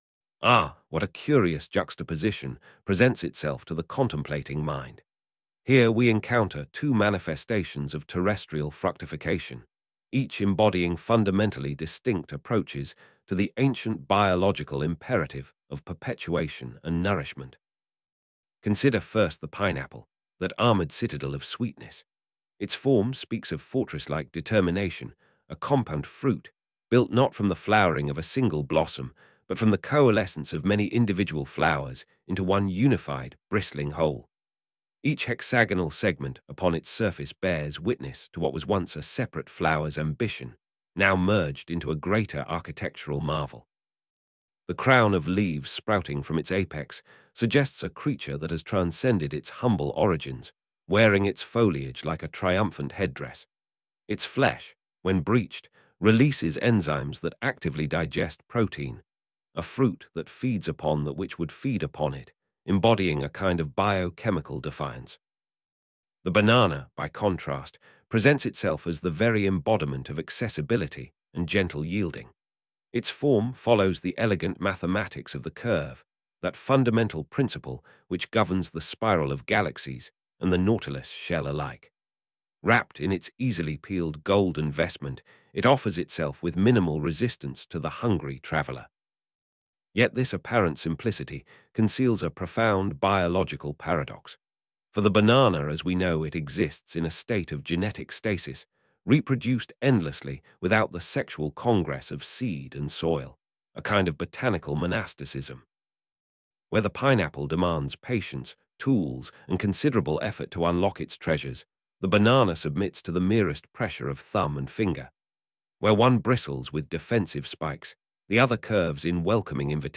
audio-narration creative-writing text-generation text-to-speech voice-customization